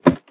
ladder2.ogg